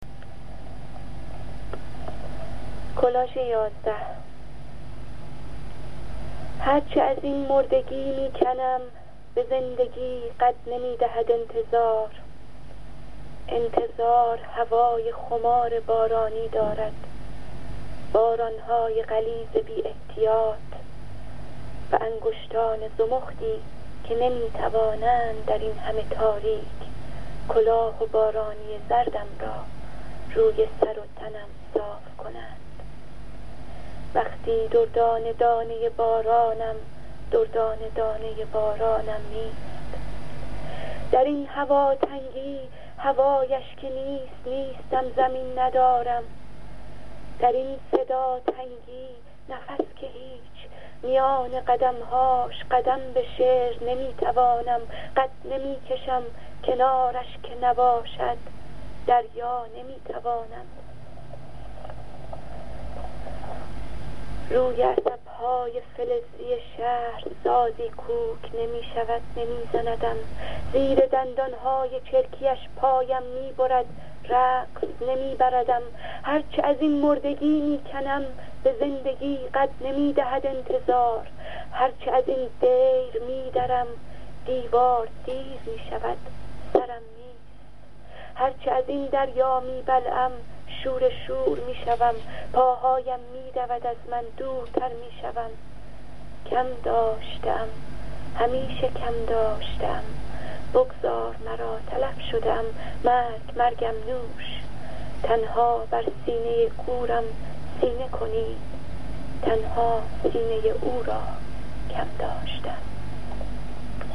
برای شنیدن این شعر با صدای شاعراین جا کلیک کنید